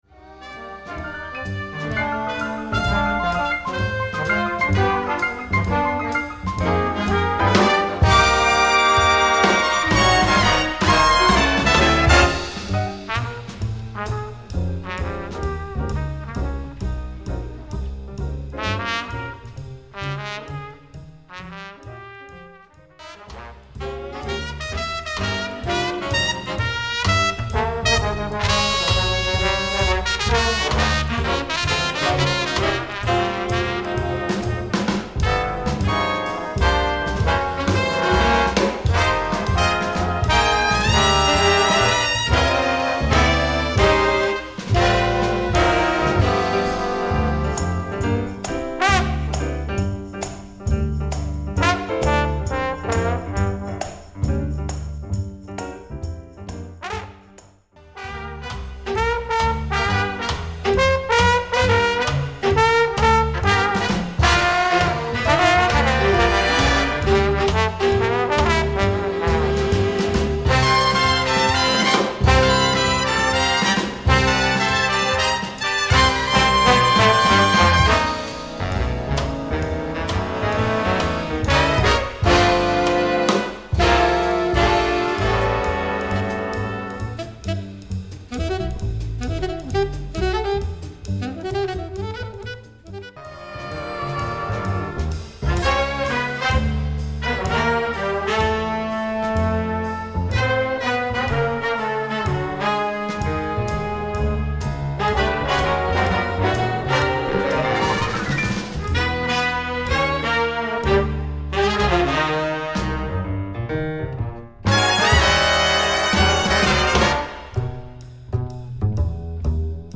Lead Trumpet Range: high E
Solos: piano, trumpet, trombone, alto sax, bass